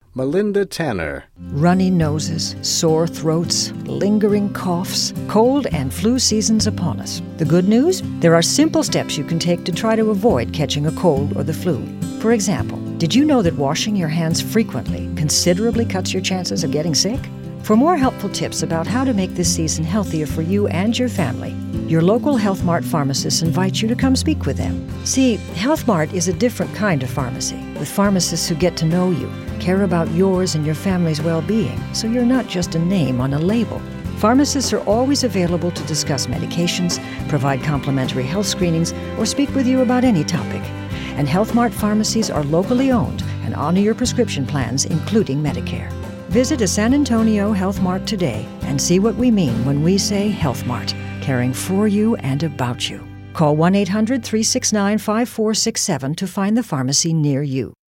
commercial : women